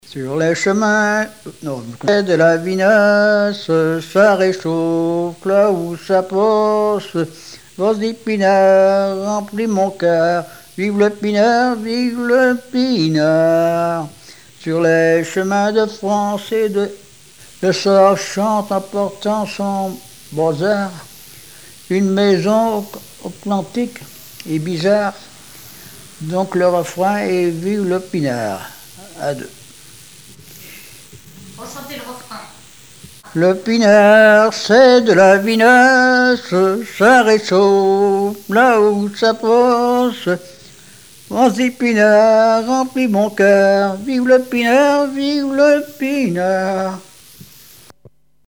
Mémoires et Patrimoines vivants - RaddO est une base de données d'archives iconographiques et sonores.
Répertoire de chansons populaires et traditionnelles
Pièce musicale inédite